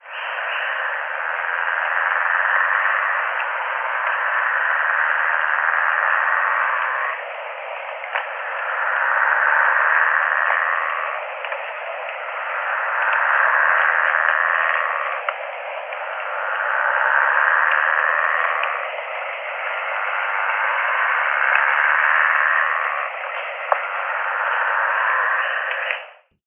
Play call
Its presence can best be detected by its distinctive call which is a continuous, low, rasping sound (with each louder portion of the call lasting around 3-4 seconds); this can sometimes be heard in the late afternoon, especially during overcast, cloudy conditions.
limnonectes-plicatellus.mp3